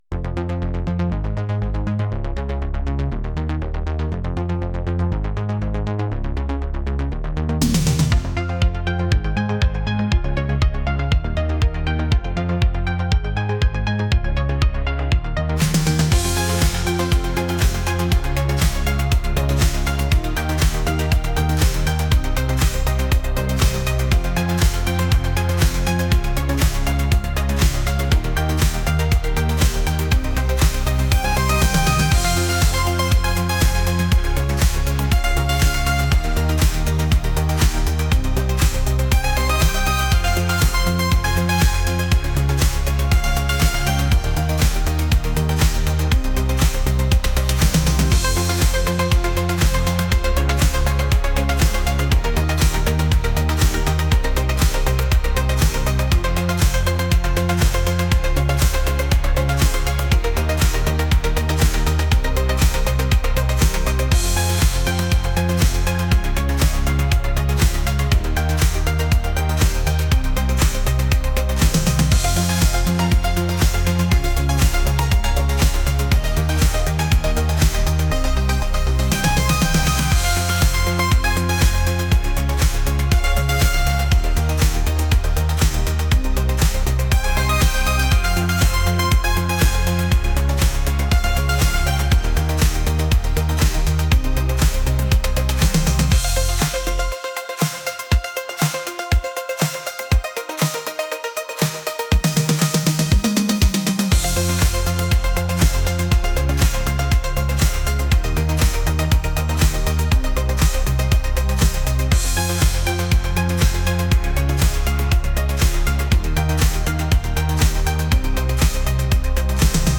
upbeat